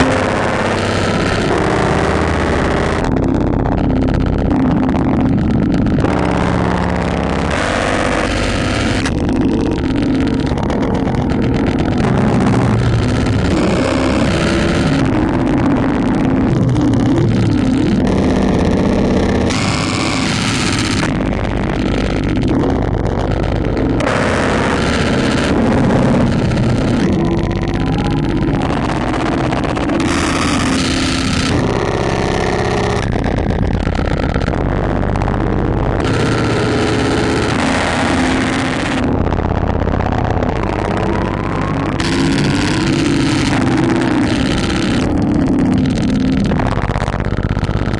描述：工业电子循环，合成无人机，哔哔声气氛
Tag: 大气压 哔哔声 无人驾驶飞机 电子 工业 循环 合成